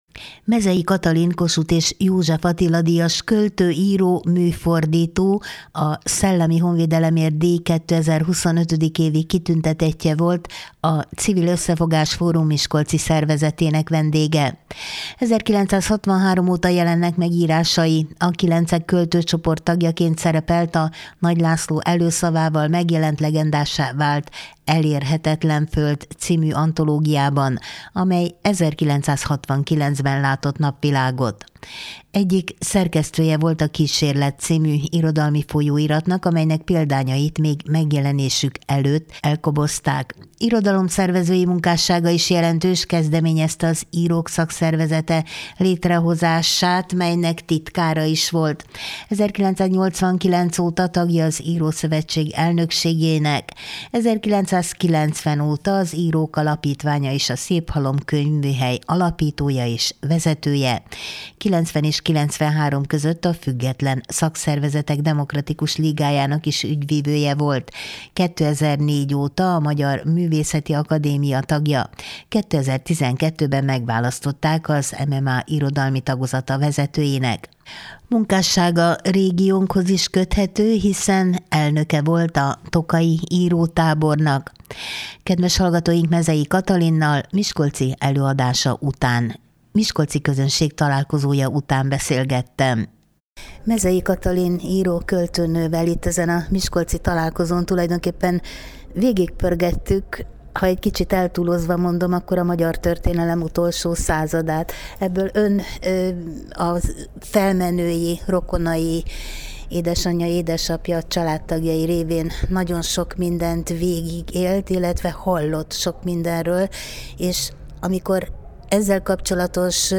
Mezey Katalin Kossuth és József Attila-díjas költő, író, műfordító, a Szellemi Honvédelemért Díj 2025. évi kitüntetettje volt a Civil Összefogás Fórum miskolci szervezetének vendége.